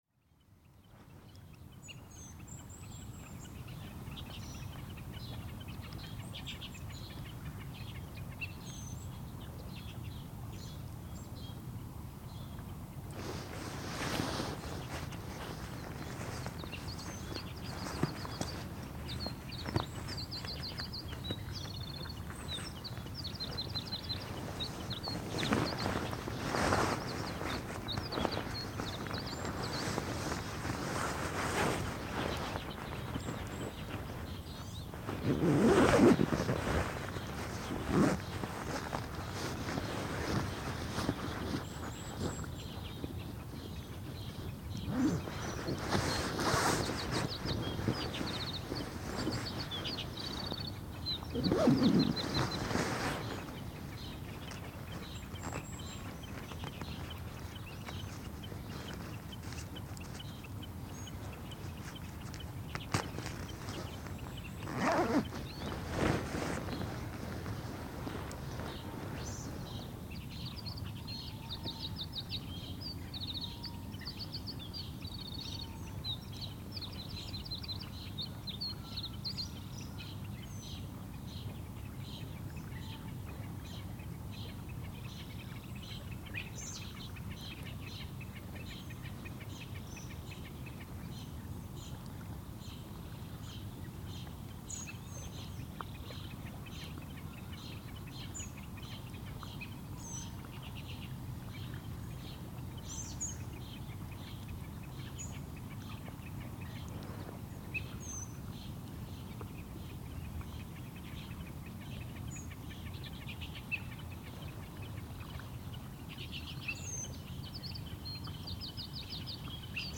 But, just as in the past, the soundscape was glorious. Two MKH20 was placed close to the lake and I went to sleep 10 meters away. The time was about 5:40 in the morning and the atmosphere was very quiet in the fog when the recording start.